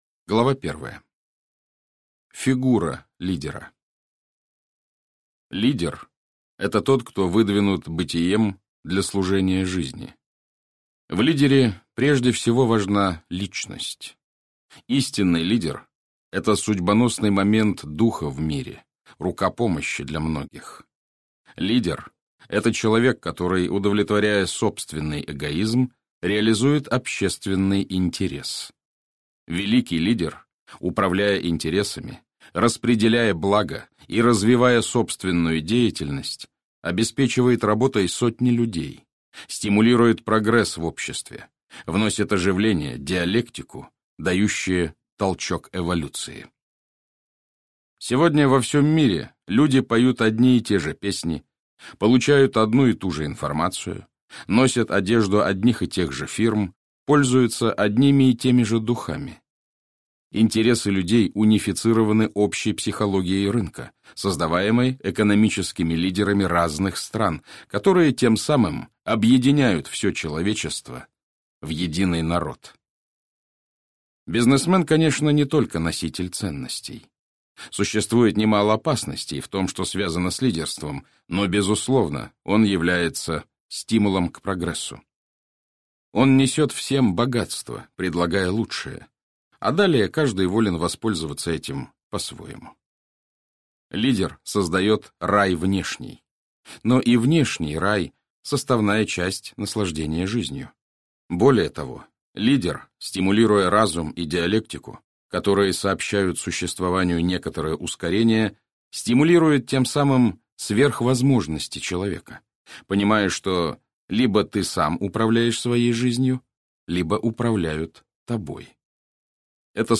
Aудиокнига Психология лидера (избранные главы) Автор Антонио Менегетти Читает аудиокнигу Александр Клюквин.